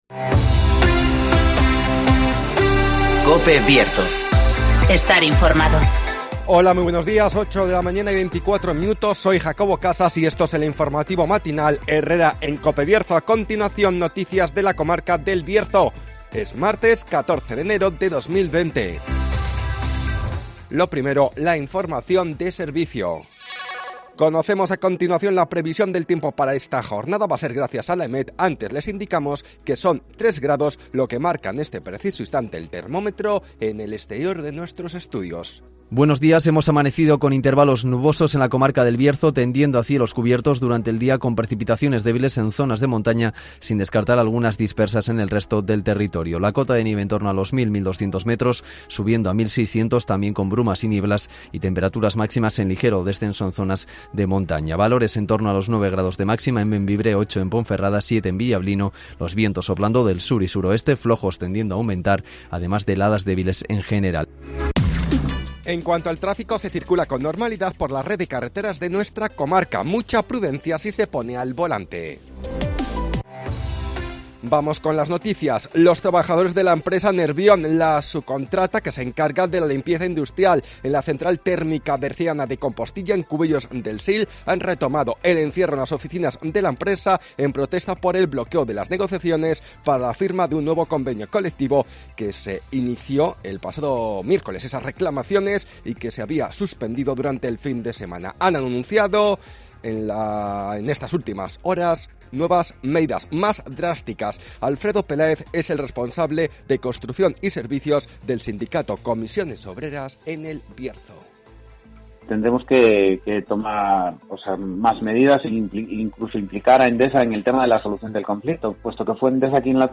INFORMATIVOS BIERZO
Conocemos las noticias de las últimas horas de nuestra comarca, con las voces de los protagonistas